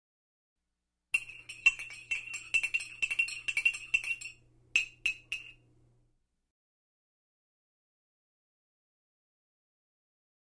Звуки чашки
Шум помешивания кофе или чая